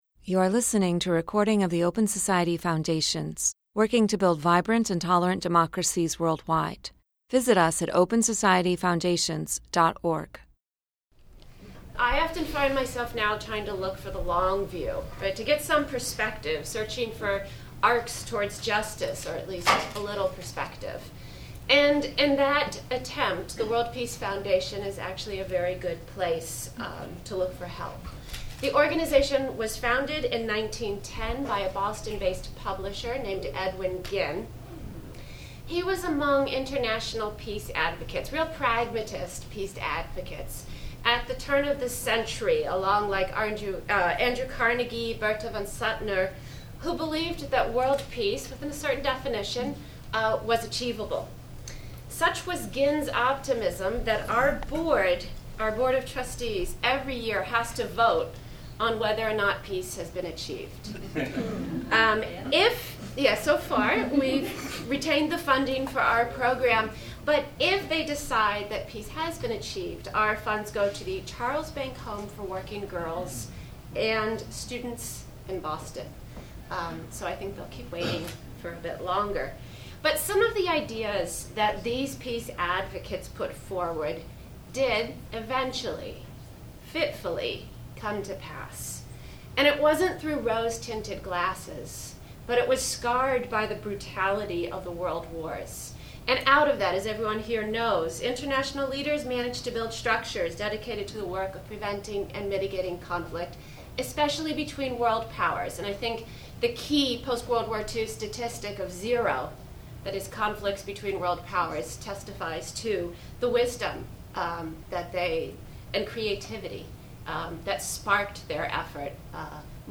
Corruption, Jobs, and the Arms Trade: Indefensible Book Launch and Panel Discussion
An expert panel discusses the risks and tradeoffs of the Trump administration’s proposed military buildup and budget rebalancing away from international assistance and foreign aid.